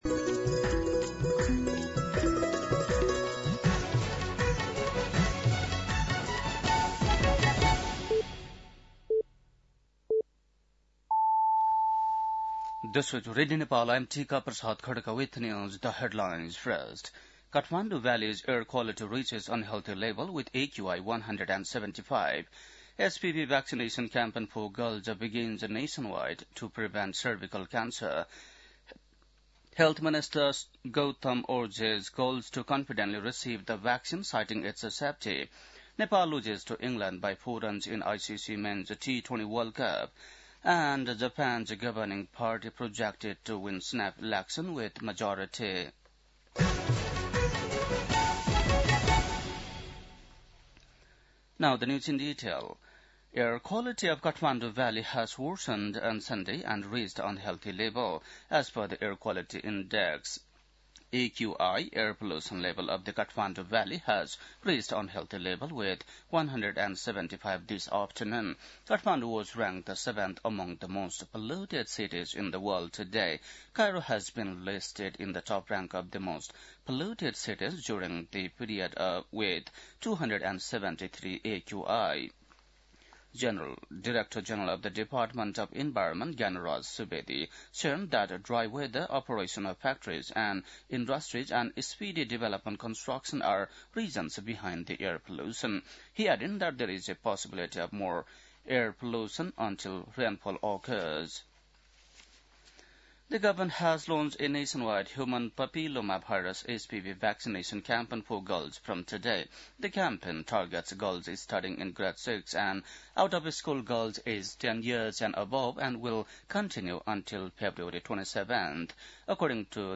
बेलुकी ८ बजेको अङ्ग्रेजी समाचार : २५ माघ , २०८२
8.-pm-english-news-1-2.mp3